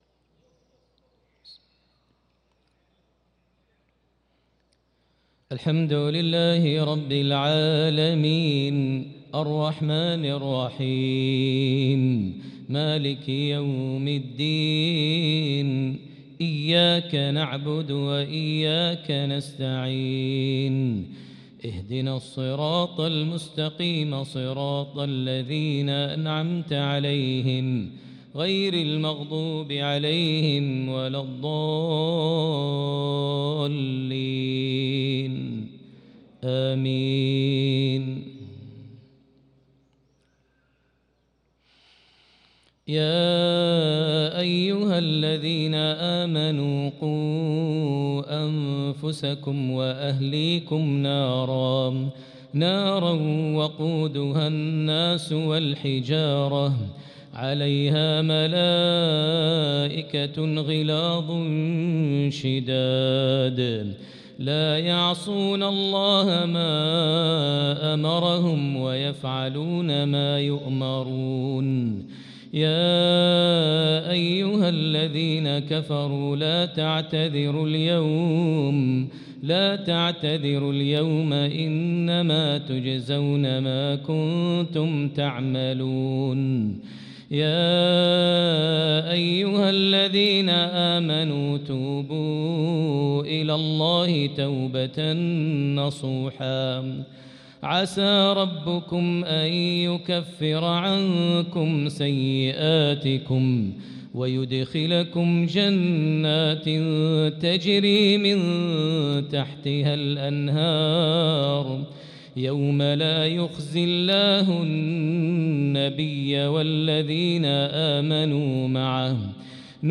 صلاة العشاء للقارئ ماهر المعيقلي 7 شعبان 1445 هـ
تِلَاوَات الْحَرَمَيْن .